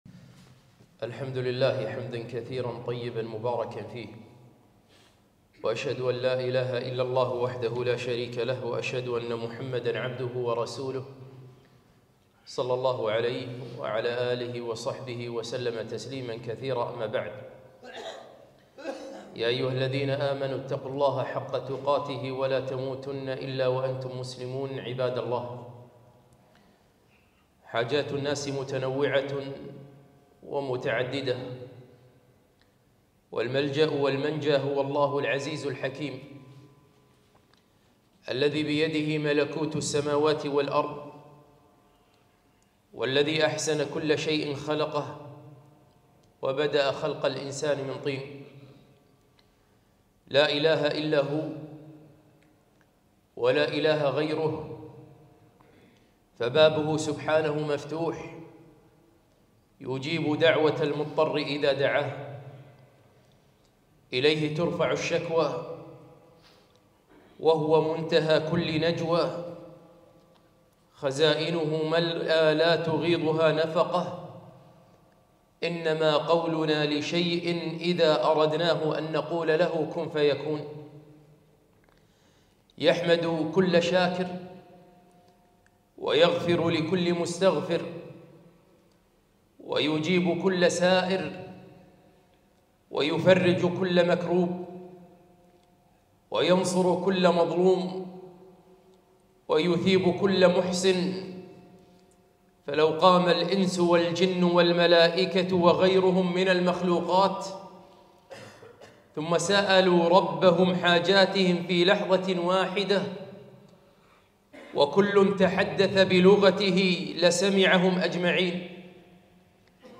خطبة - ادعوا ربكم فأنتم في العشر الأواخر